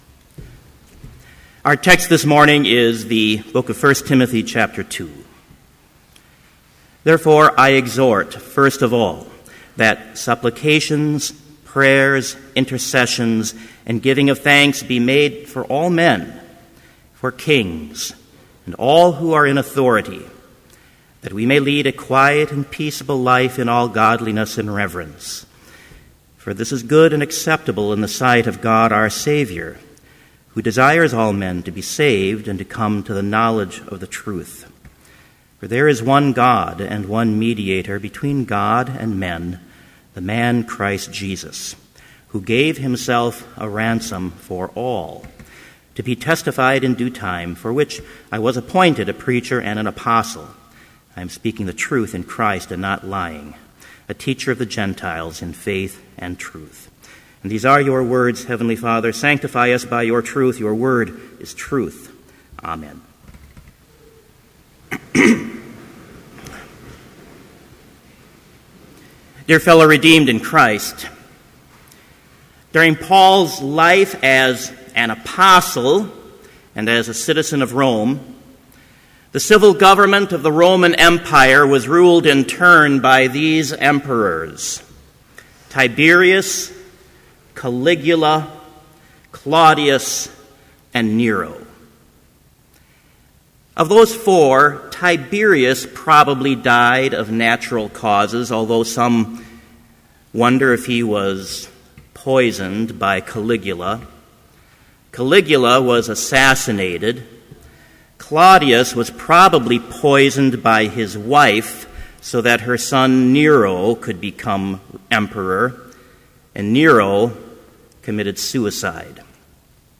Complete Service
• Homily
This Chapel Service was held in Trinity Chapel at Bethany Lutheran College on Tuesday, November 5, 2013, at 10 a.m. Page and hymn numbers are from the Evangelical Lutheran Hymnary.